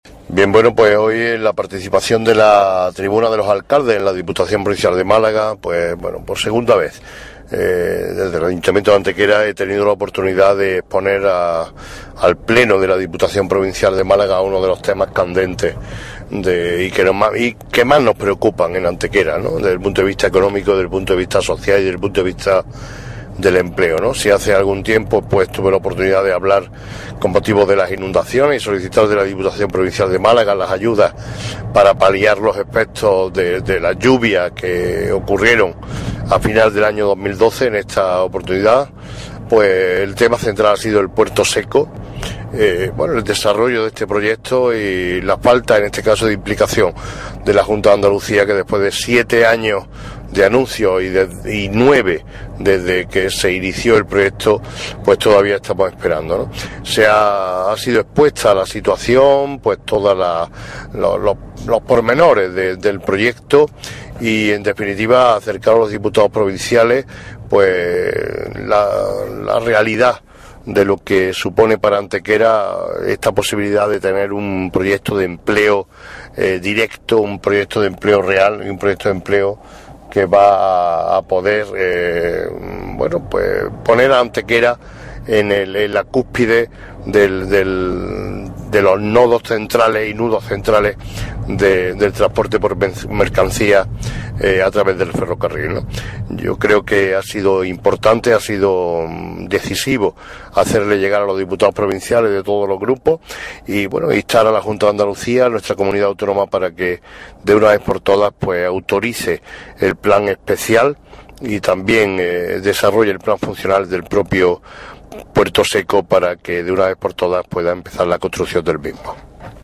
El Alcalde de Antequera interviene en el Pleno de la Diputación de Málaga en defensa y apoyo al Puerto Seco como iniciativa económica dinamizadora de la Comarca y la Provincia
La intervención del Alcalde de Antequera, realizada a comienzos del Pleno, ha tratado sobre la situación actual del Puerto Seco, proyecto que Barón ha calificado como “decisivo” para el positivo desarrollo socioeconómico de nuestro Municipio, nuestra Comarca y la provincia de Málaga en sí.